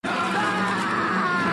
Greyjoy Battlecry